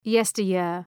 {‘jestər,jıər}